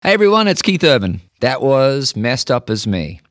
LINER Keith Urban (Messed Up As Me) 7